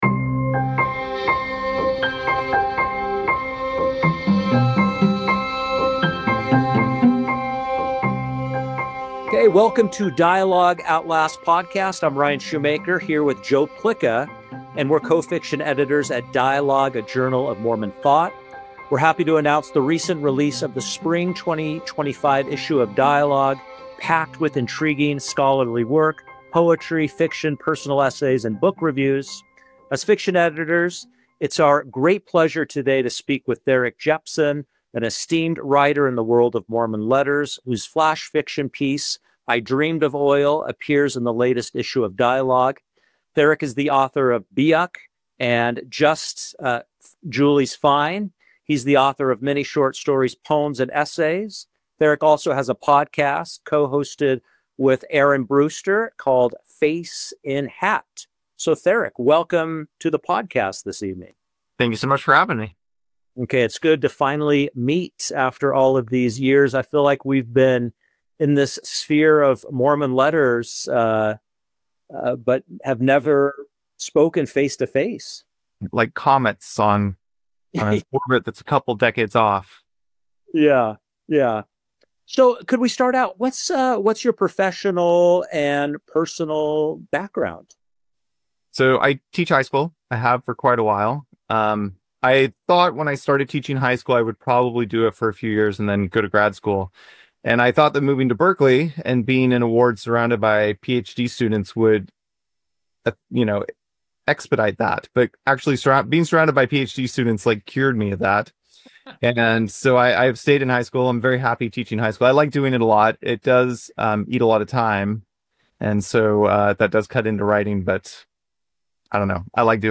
Dialogue fiction editors